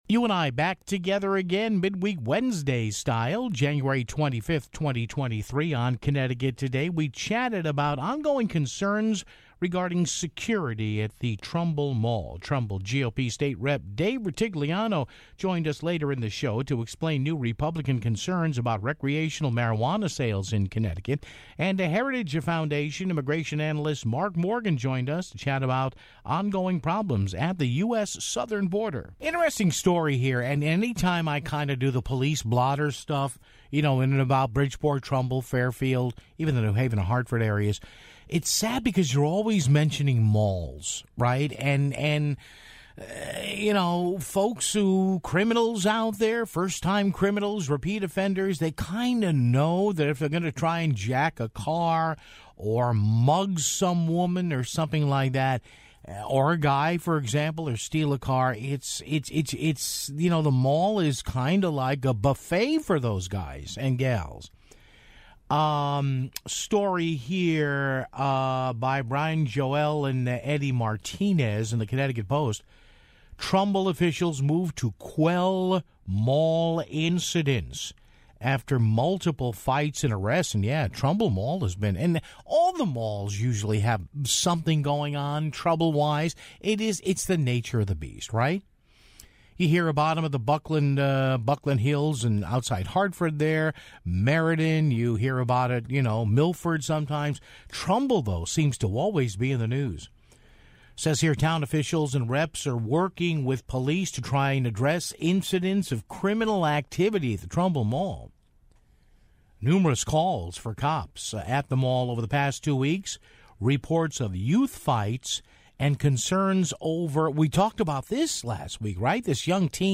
Trumbull GOP State Rep. David Rutigliano explained new Republican concerns about recreational marijuana sales in Connecticut (11:44).